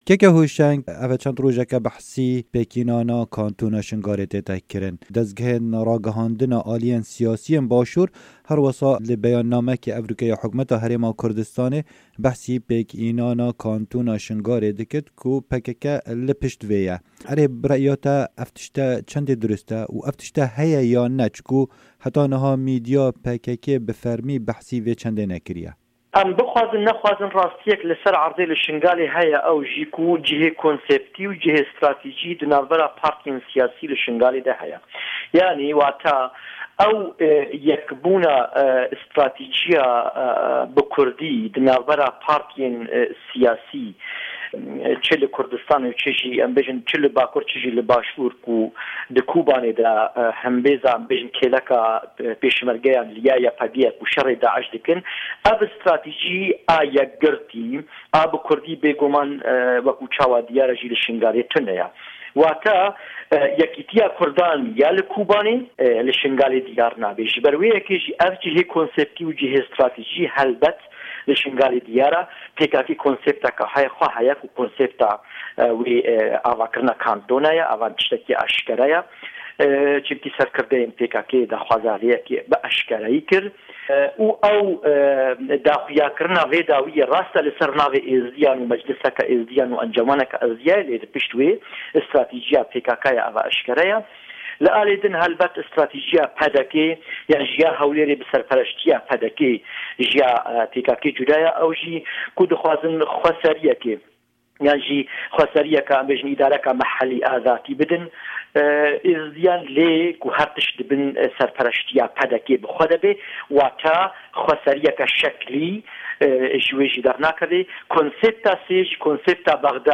hevpeyvin